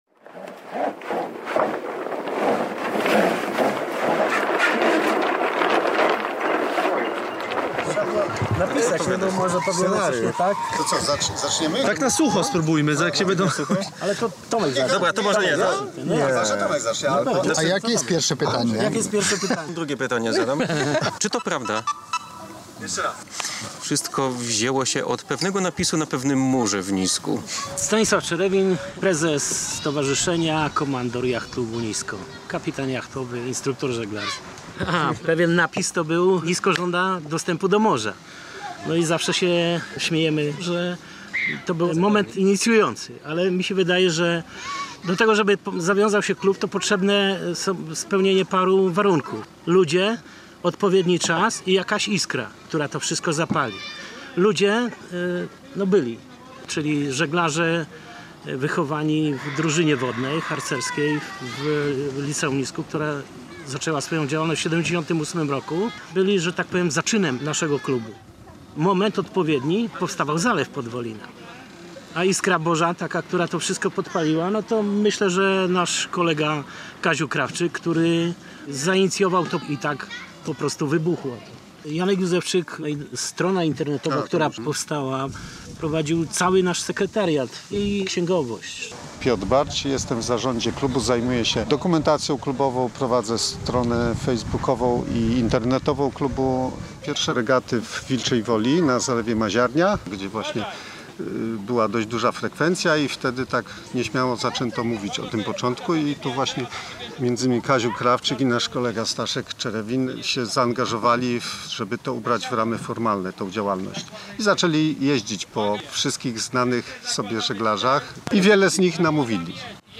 Jacht Klub Nisko świętuje 15-lecie działalności • Spotkanie z reportażem • Polskie Radio Rzeszów